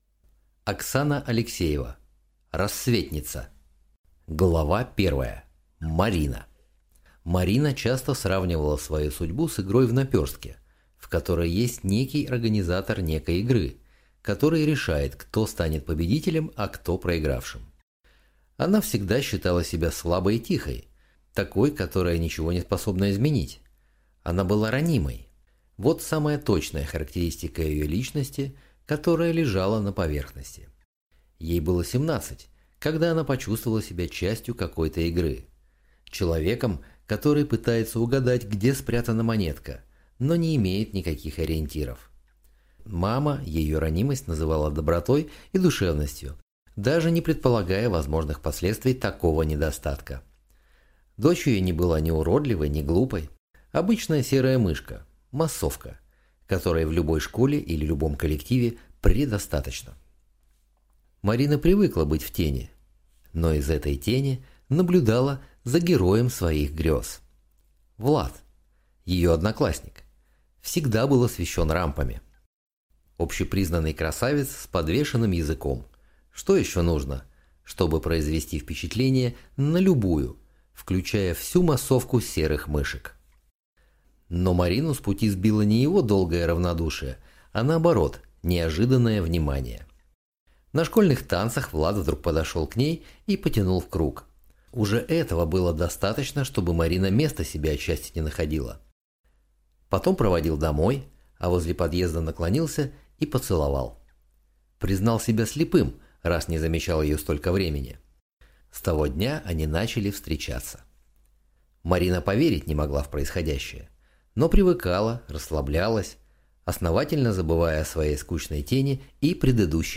Aудиокнига Рассветница